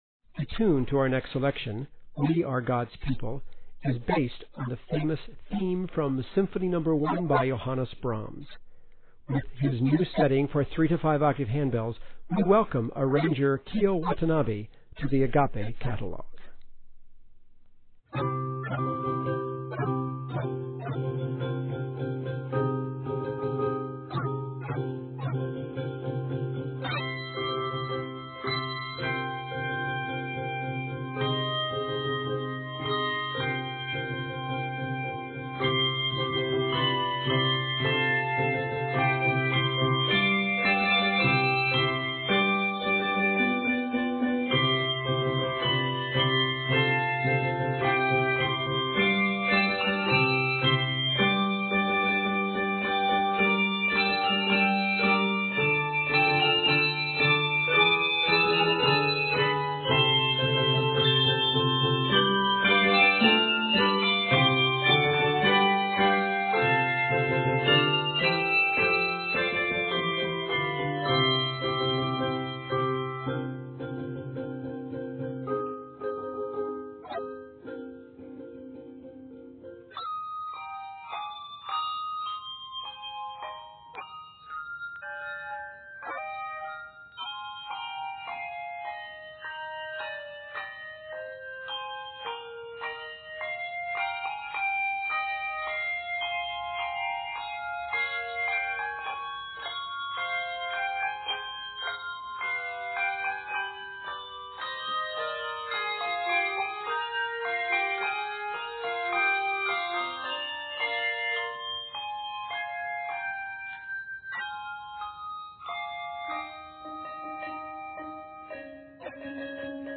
set here for 3-5 octave handbells